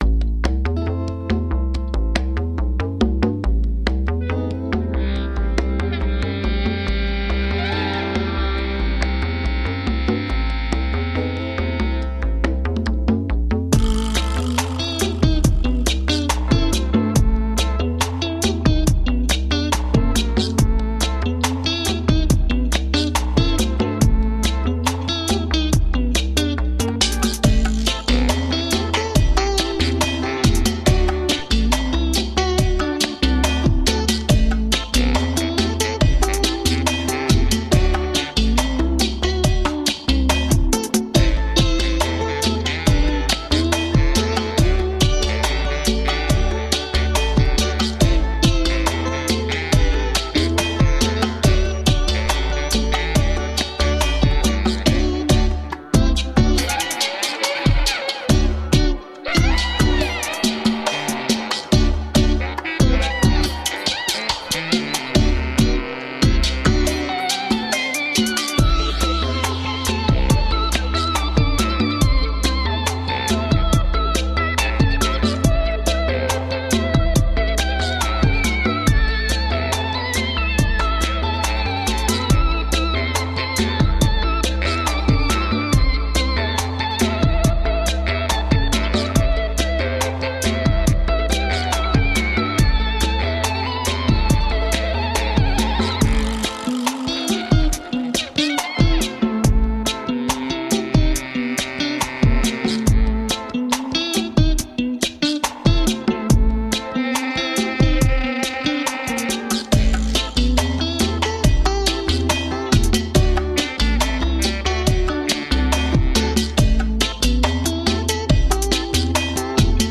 Tags: Cumbia , Tropical
futuristic electronic cumbia
baritone and clarinet